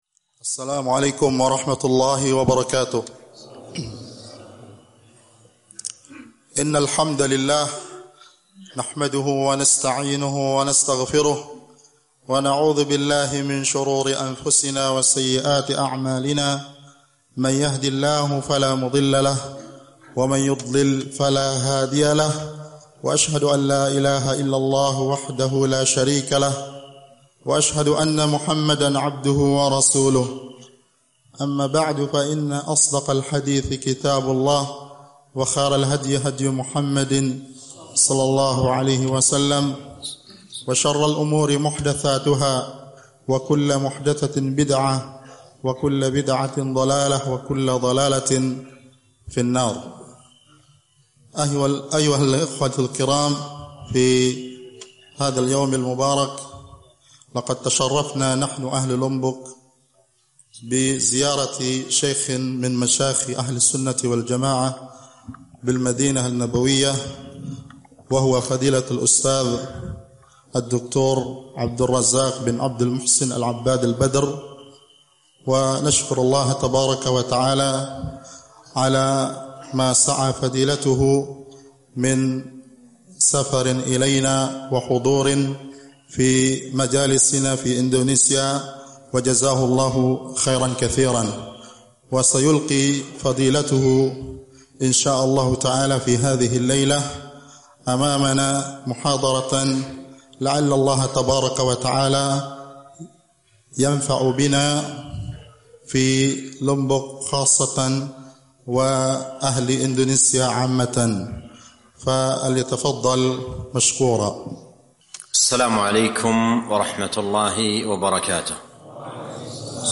الإسلام دين الأخلاق - محاضرة